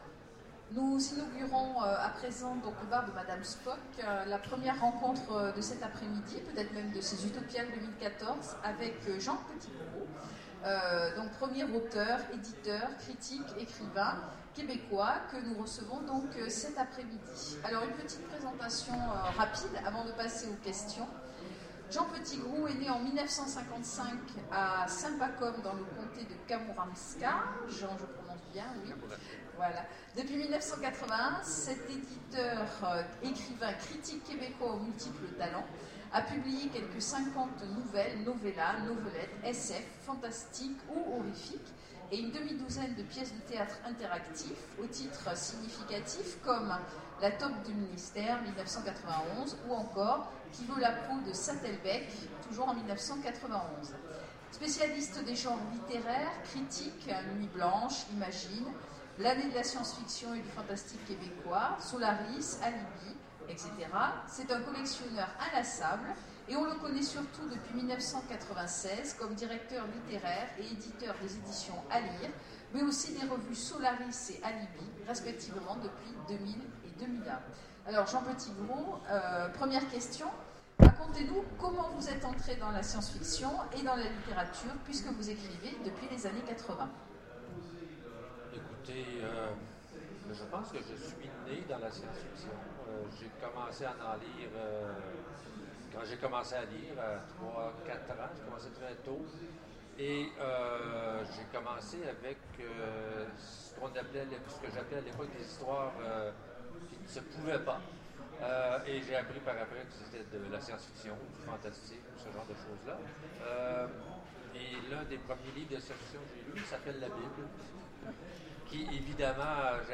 Mots-clés Rencontre avec un auteur Rencontre avec une maison d'édition Conférence Partager cet article